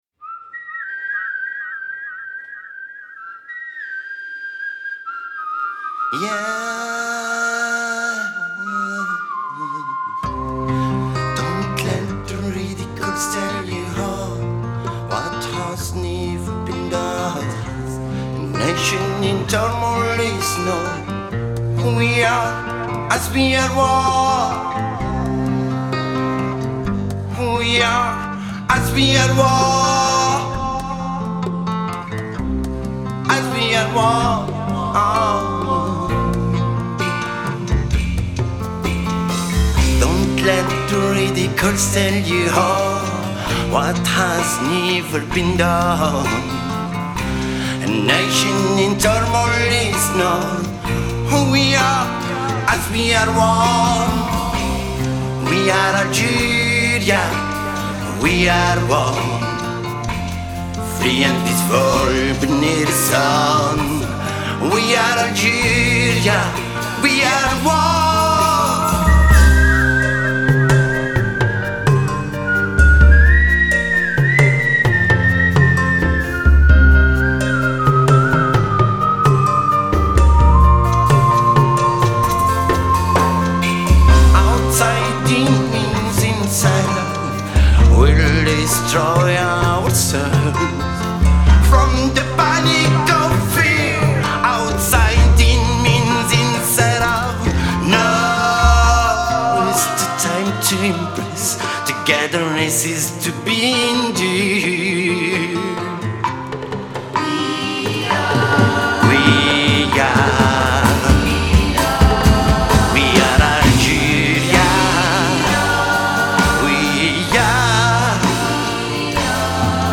Vocals, Traditional Percussion
Percussion, Keyboard, Bass, Drum set, Percussion, Whistling